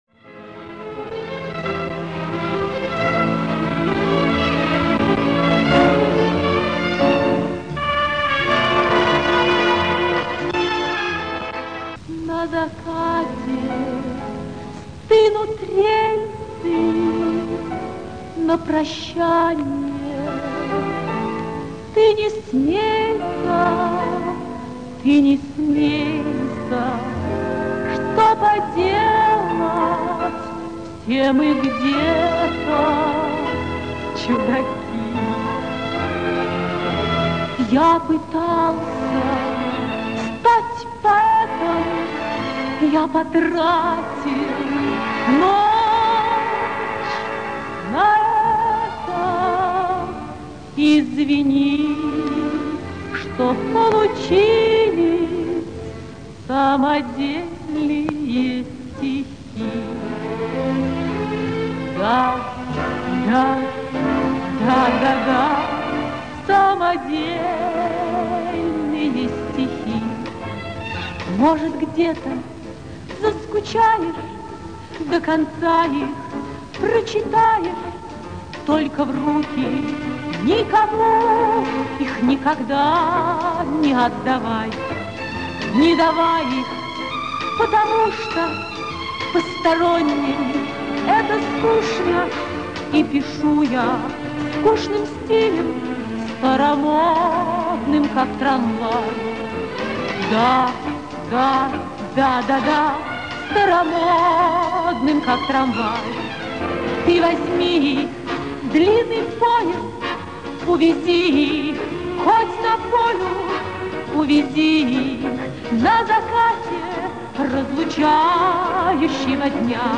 Качество то же не ахти